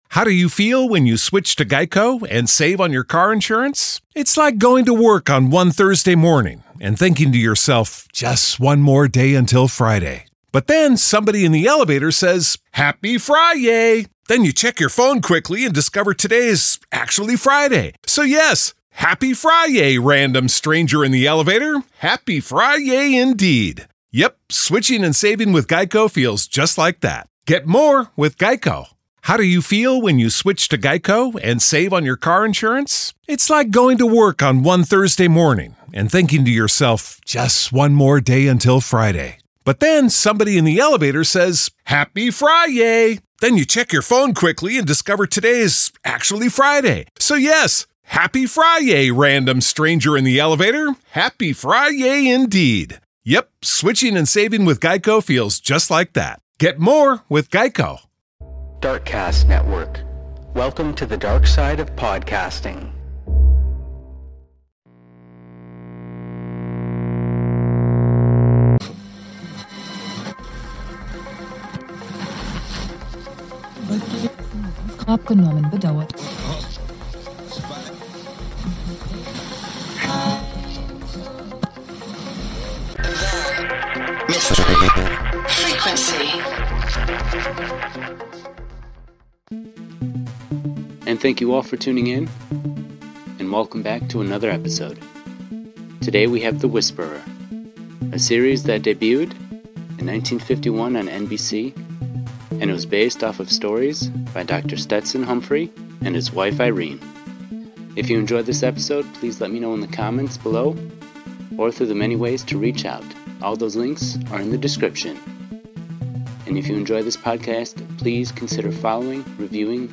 1 .422 Old Time Radio Show | The Whisperer [Vol 1] Classic Detective Audio Drama 50:20